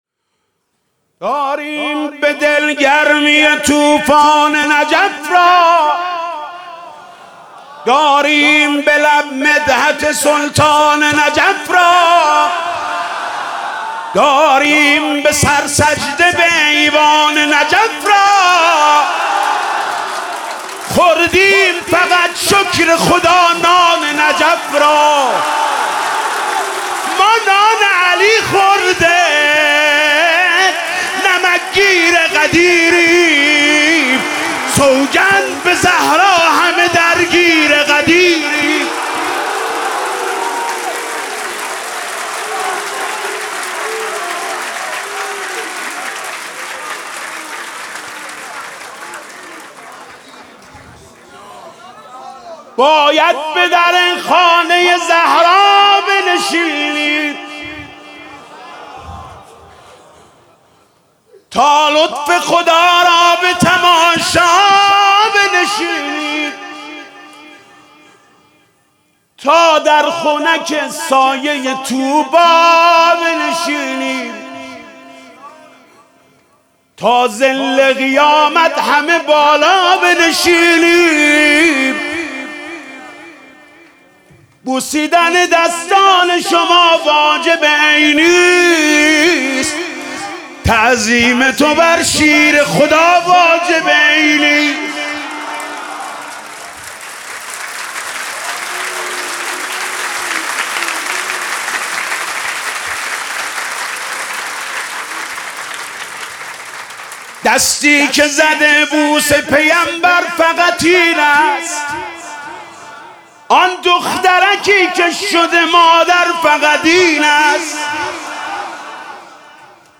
مدح: داریم به دل گرمی طوفان نجف را!